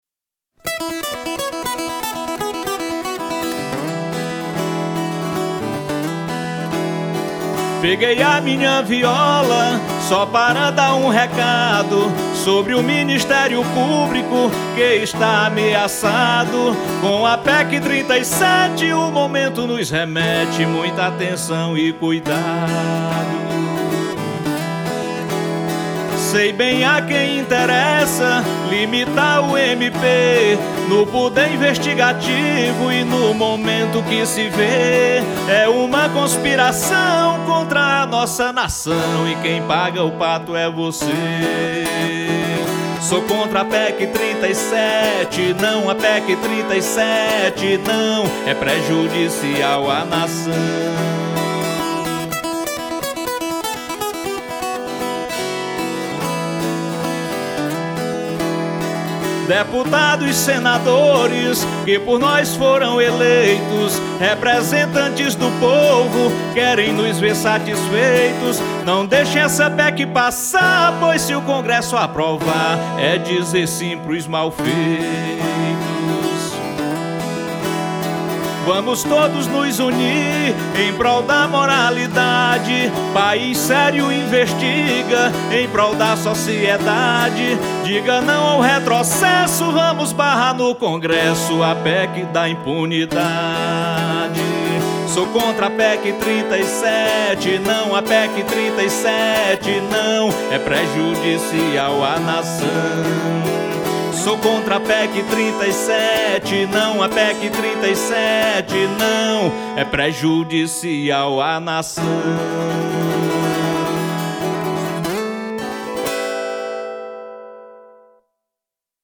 canción de apoyo a la causa.